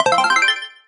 sfx_ready_go.mp3